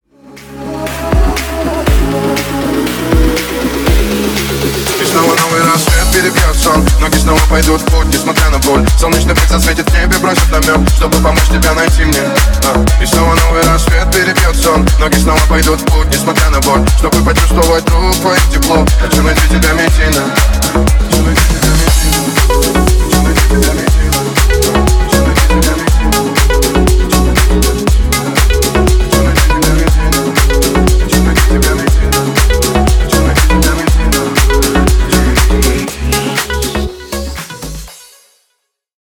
Ремикс # Поп Музыка
ритмичные